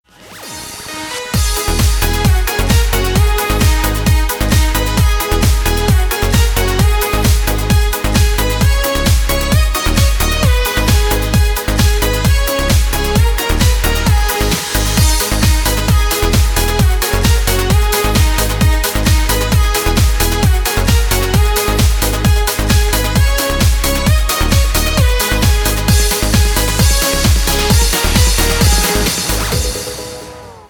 зажигательные
веселые
Electronic
EDM
без слов
энергичные
Стиль: electro house